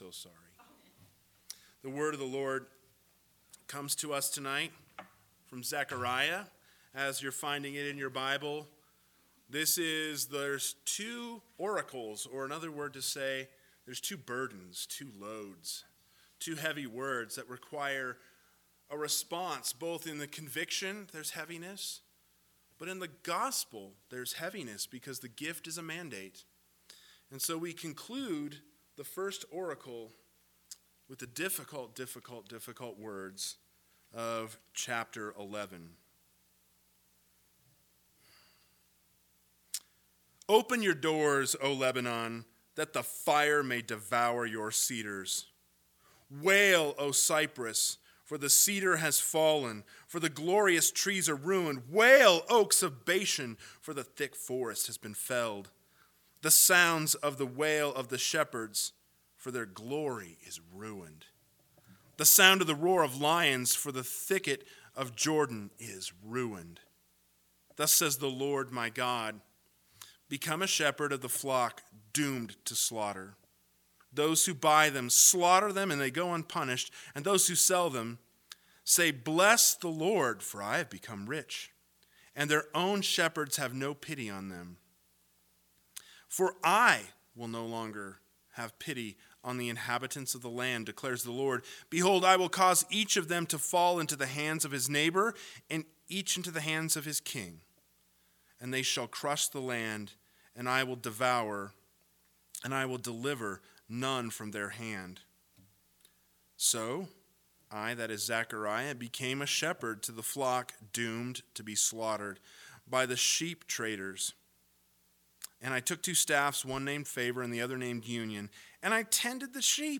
PM Sermon – 03/07/2021 – Zechariah 11 – Can Sheep Be Saved from the Slaughter?